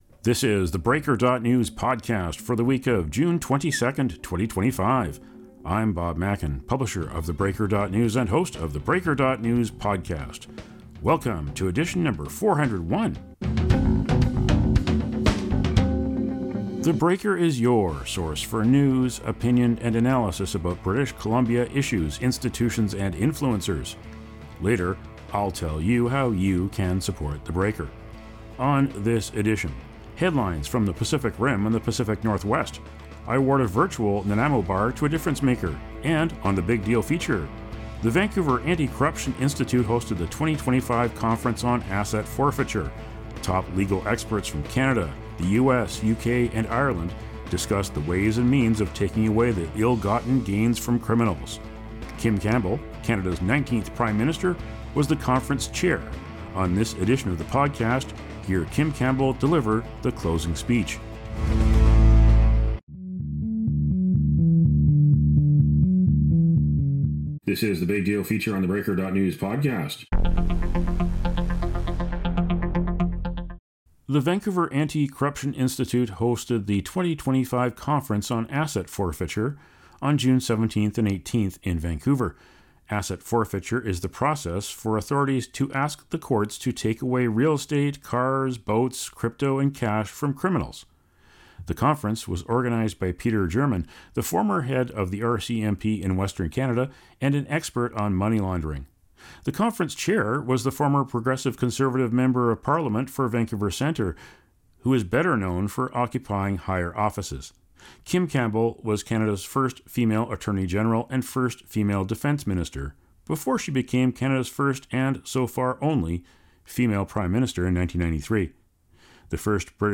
In the closing address of the two-day conference, Campbell sounded the alarm about the rule of law and privacy under Donald Trump’s second presidency.